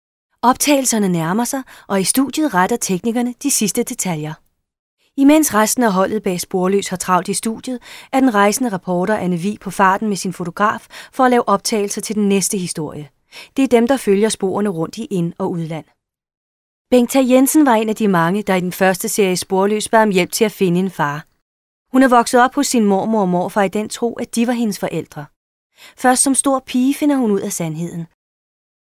Kein Dialekt
Sprechprobe: Industrie (Muttersprache):
female danish voice over talent, young danish commercials cartoon-dubbing voice-over documentary internet school-material